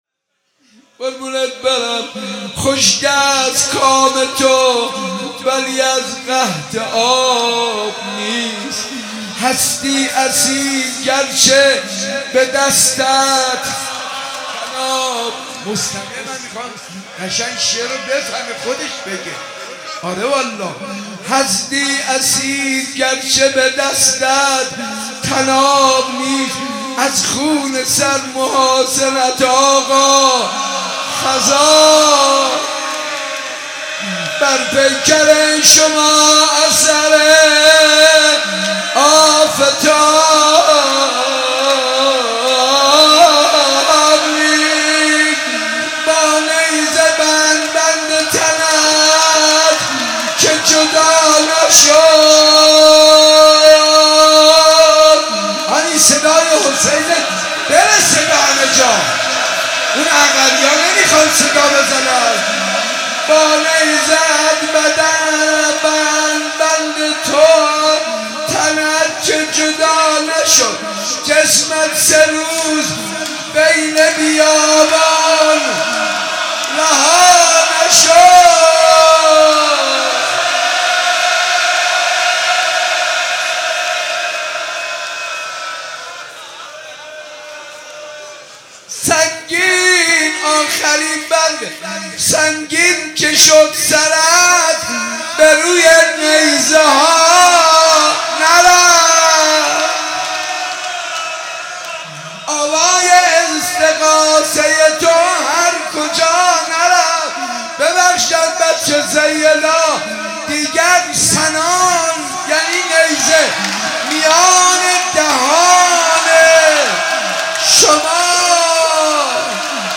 روضه‌خوانی منصور ارضی در مصائب امام عسکری(ع) صوت - تسنیم
حاج منصور ارضی مداح پیشکسوت اهل بیت(ع) در مصائب ایام یازدهم روضه‌خوانی کرد.